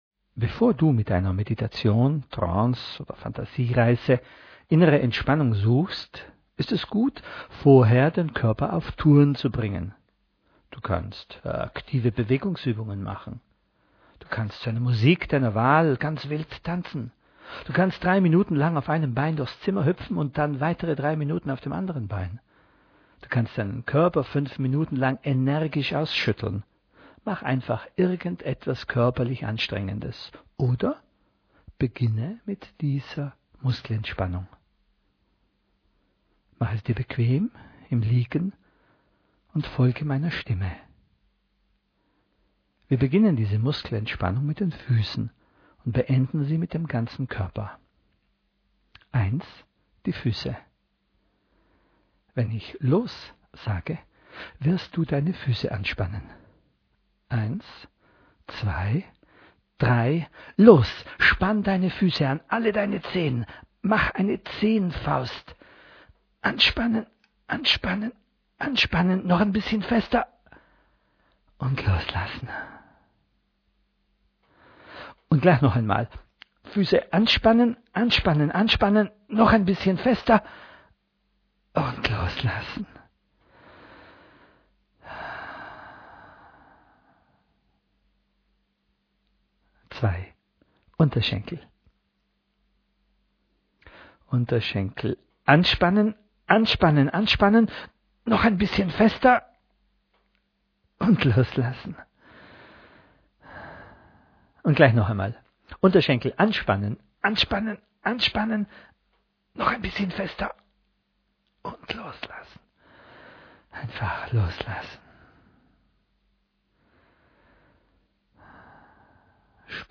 eine geführte Körpermeditation
Foto: TELOSUnsere „Muskelentspannung“, stark komprimiert, 10 Minuten, mp3-Format, 1,8 MB >>